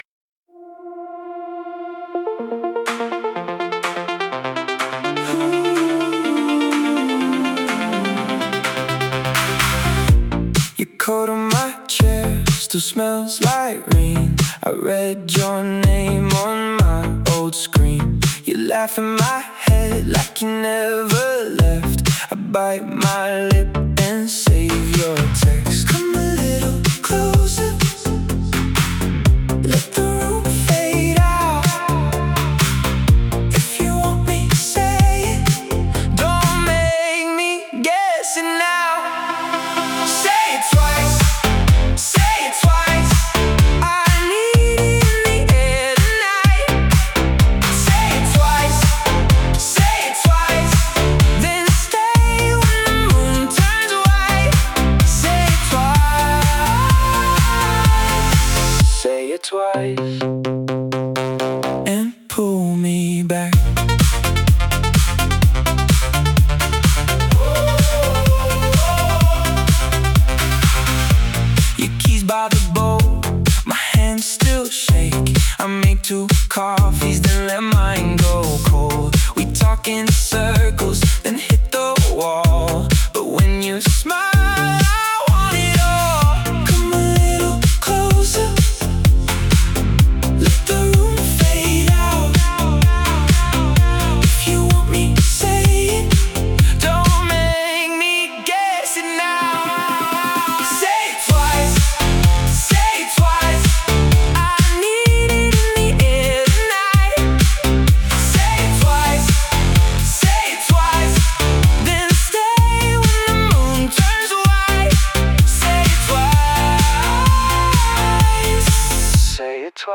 electronic pop synth-pop